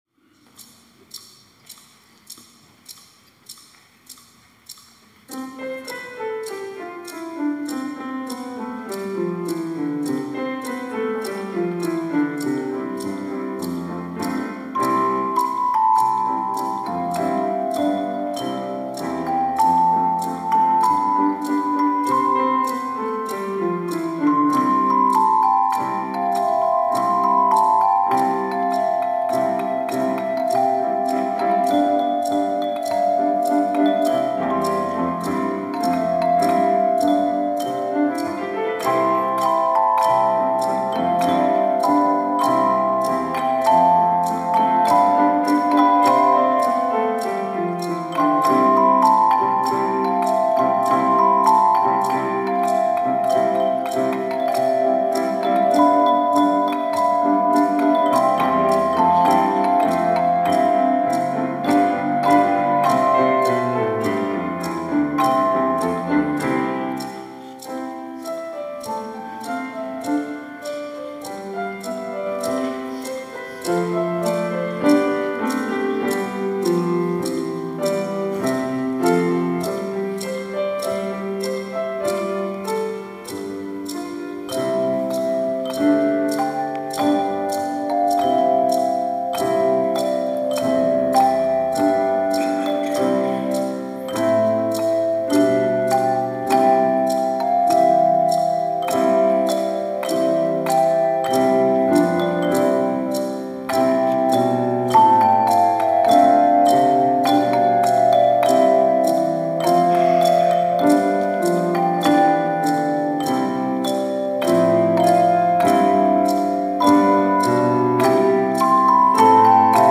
특송과 특주 - 핸드차임 성탄찬양 메들리